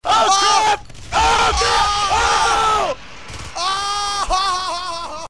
hive death